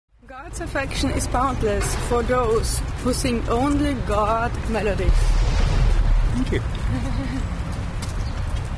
Poem of the day recited